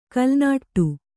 ♪ kalnāṭṭu